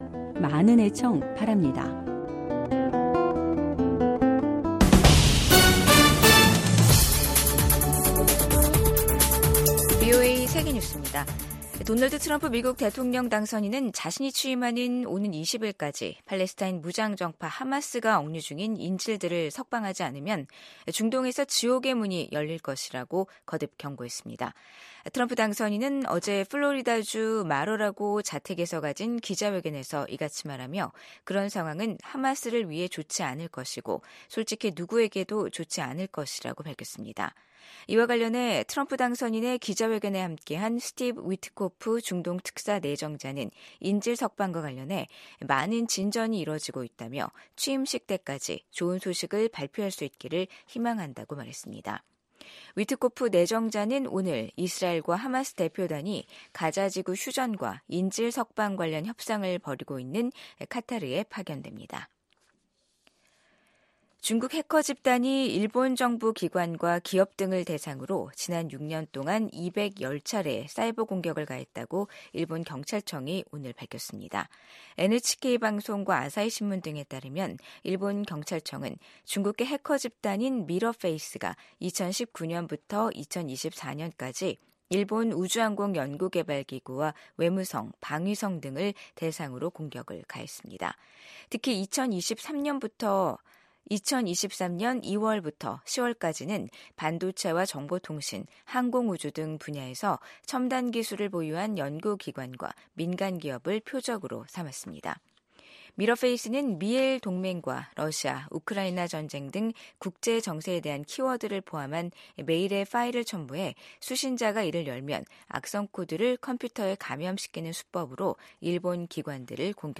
VOA 한국어 간판 뉴스 프로그램 '뉴스 투데이', 2025년 1월 8일 2부 방송입니다. 토니 블링컨 국무장관이 미한일 3국 공조가 인도태평양 지역의 안정 유지에 핵심적인 역할을 한다고 평가했습니다. 국제사회가 북한의 신형 극초음속 중거리 탄도미사일 발사를 유엔 안보리 결의 위반이라며 강력히 비판하고 나선 가운데, 미국의 미사일 전문가는 북한의 이번 미사일 시험 발사가 실패했을 가능성이 높다고 진단했습니다.